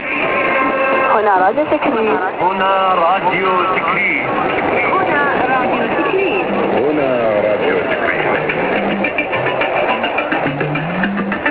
Radio Tikrit station identification ) is the latest addition in the arsenal of US-sponsored clandestine stations in the upper end of the mediumwave dial.